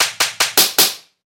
bitchslap.wav